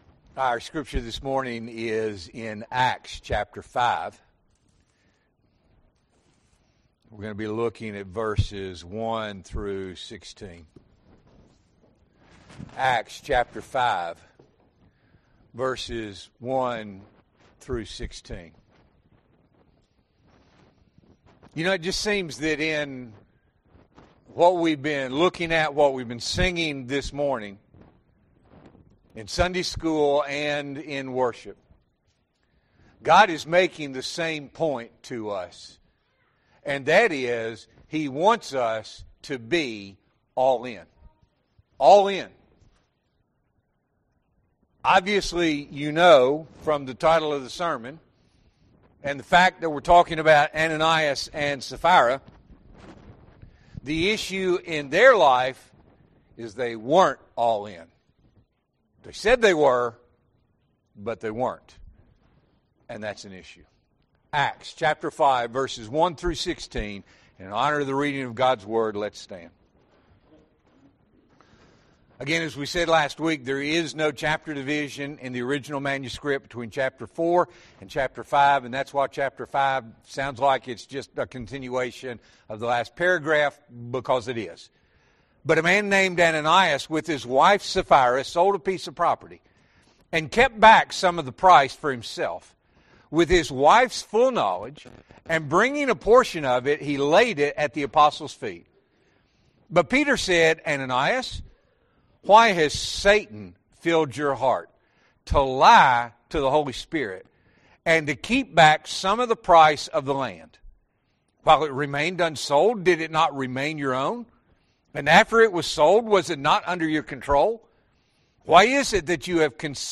January 18, 2026 – Morning Worship